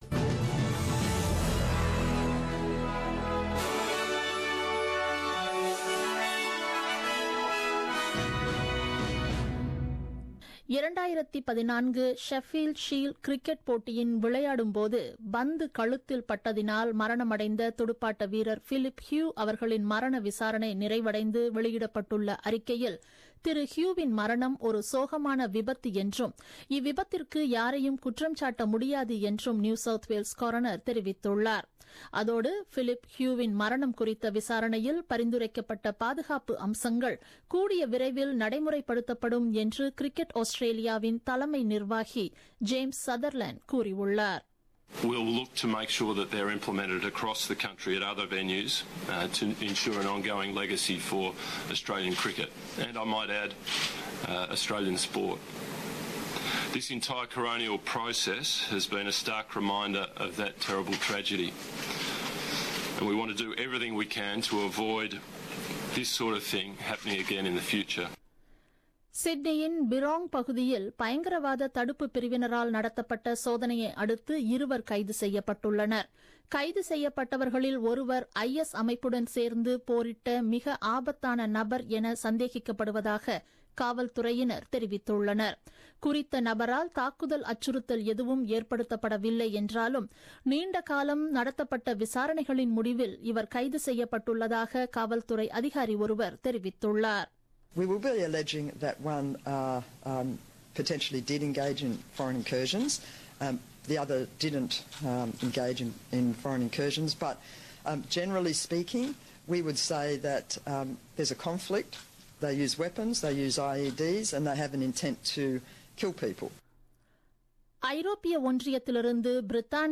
The news bulletin broadcasted on 4 Nov 2016 at 8pm.